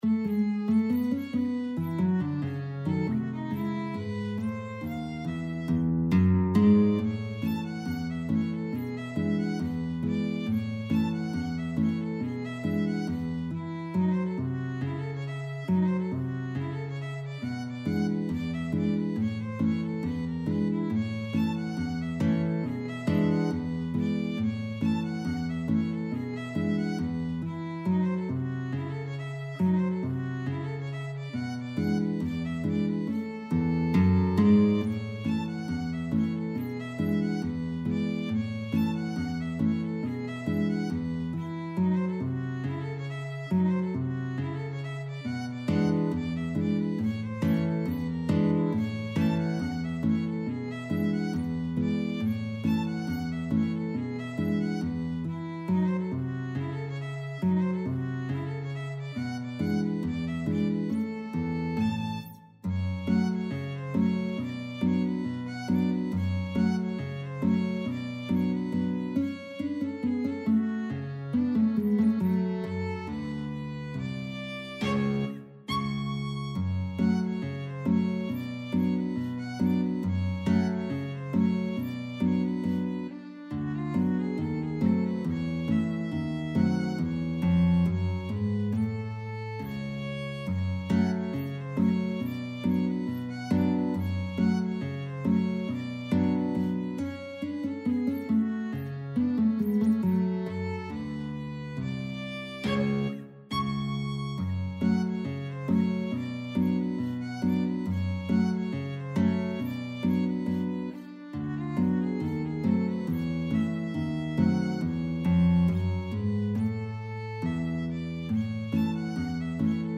2/4 (View more 2/4 Music)
Intro Slow March Tempo. = 69 Slow March Tempo
Jazz (View more Jazz Violin-Guitar Duet Music)